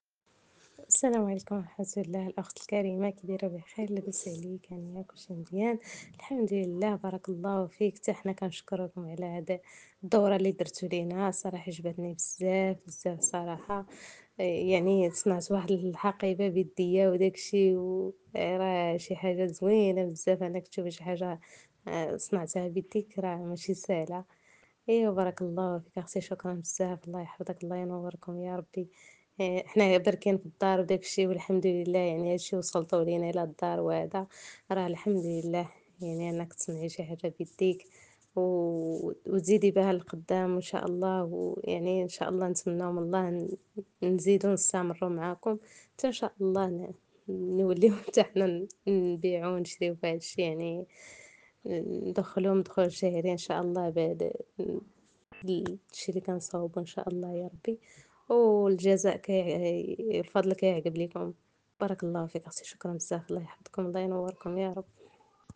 شهادات صوتية لبعض المشاركات 👇👇👇